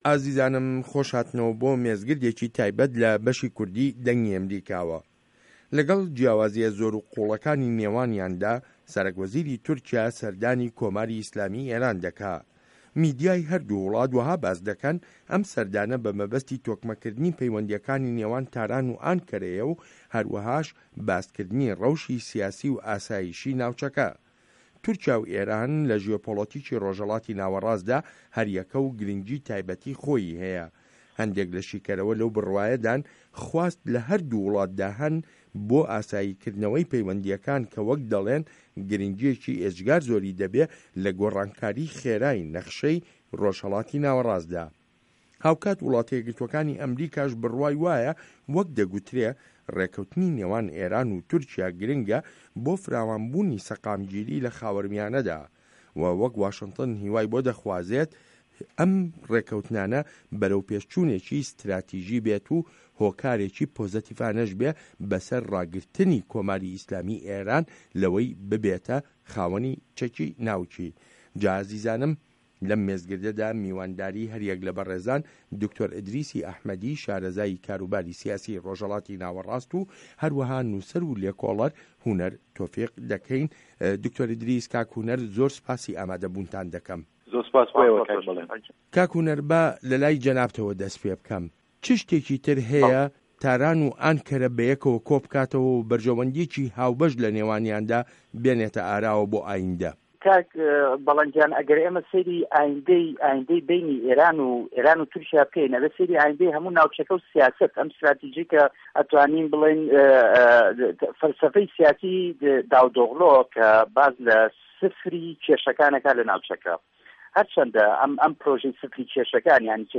مێزگرد: تورکیا و ئێران و هاوسه‌نگی هێز له‌ ڕۆژهه‌ڵاتی ناوه‌ڕاستدا